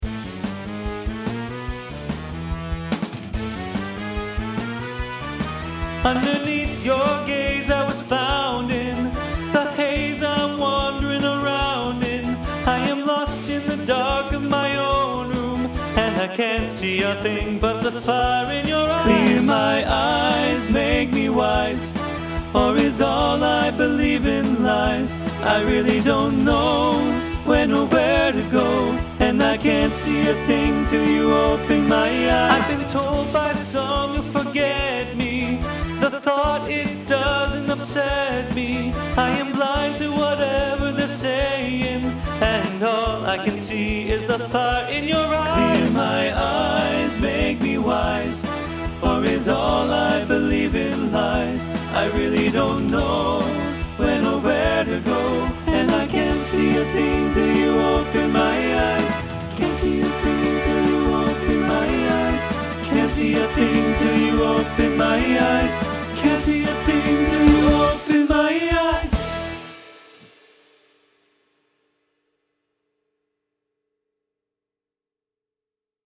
Voicing TTB Instrumental combo Genre Rock
Mid-tempo